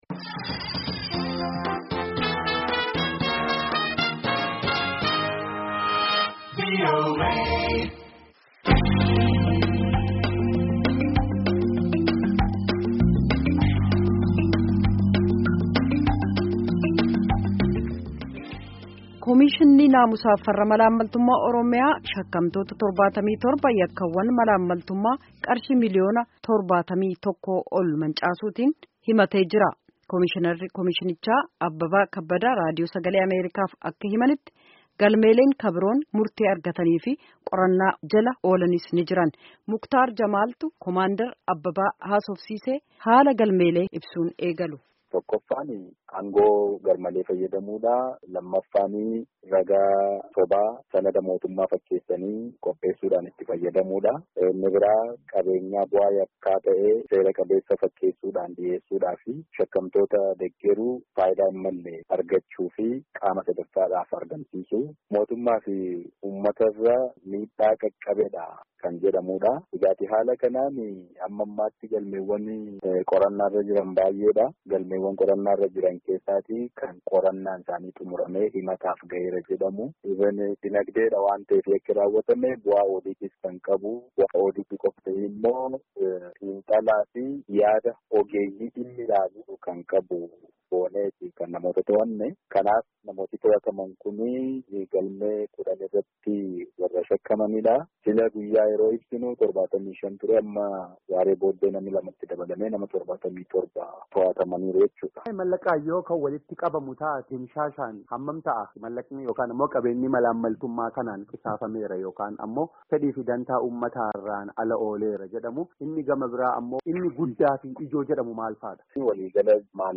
Gaafii fi Deebii Koomishinara Koomishinni Naamusaa fi Farra Malaammaltummaa Oromomiyaa Waliin Godhame